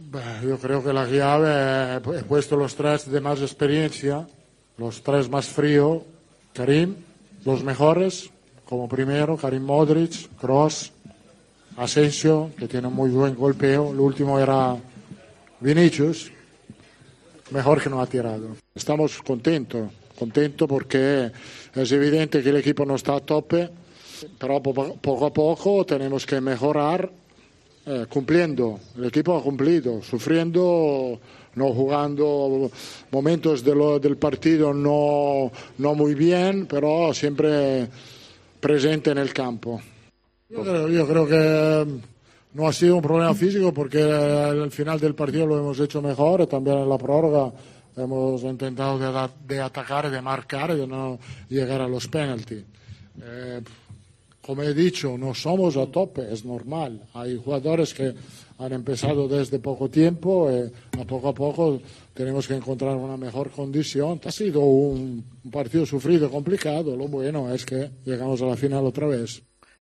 Ancelotti, bromeando: "El quinto lo hubiera tirado Vinicius... menos mal que no lo ha tirado"